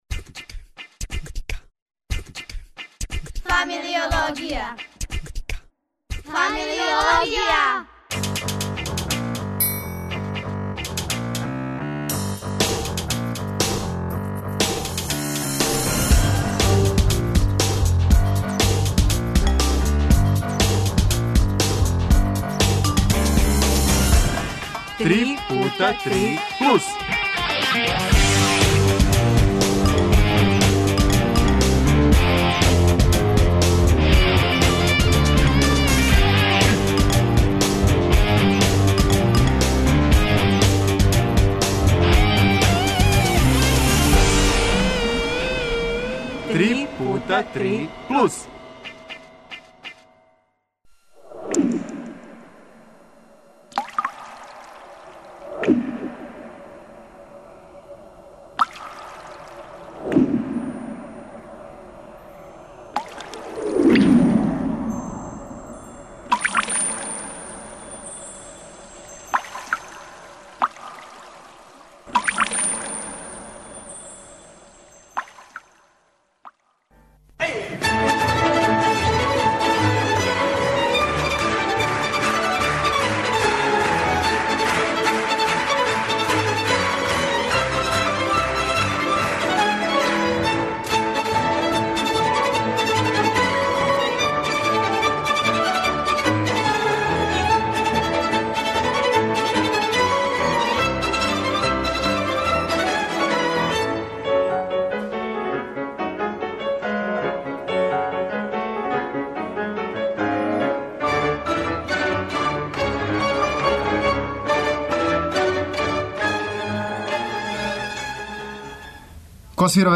Биће нам гости Италијани, мали и велики, и они који га воле и уче...